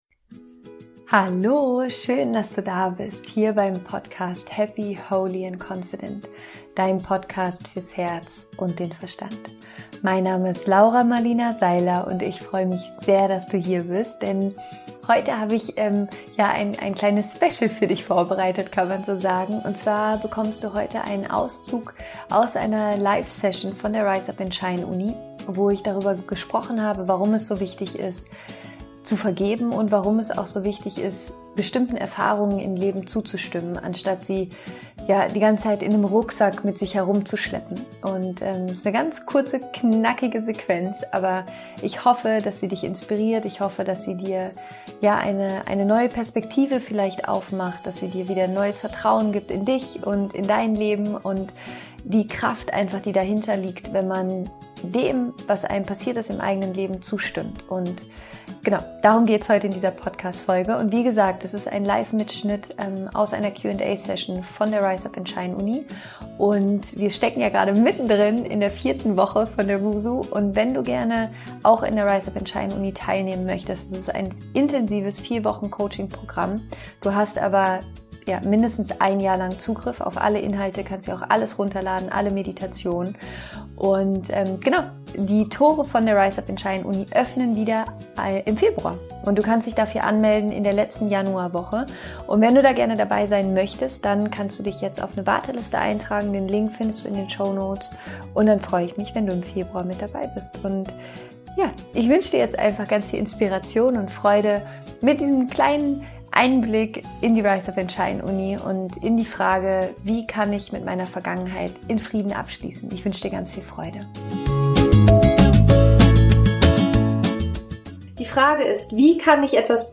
Heute wartet im Podcast ein Live-Mittschnitt aus der Rise Up & Shine Uni auf dich, in dem ich darüber spreche, warum es so wichtig ist, Frieden mit der Vergangenheit zu schließen.